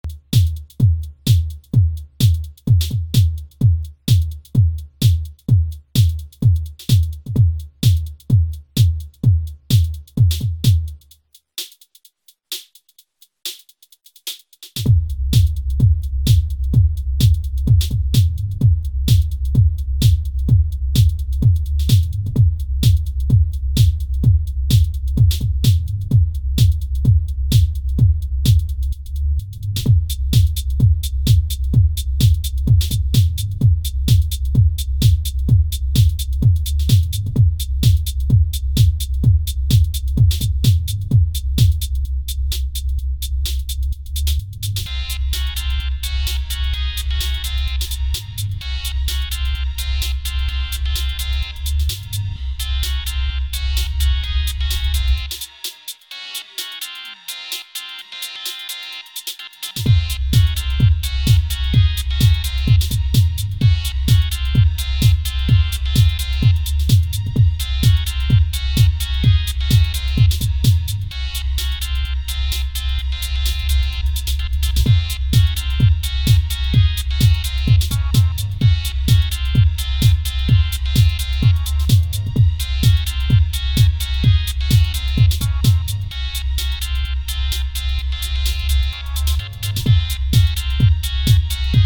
Here’s mine, only alloy machines.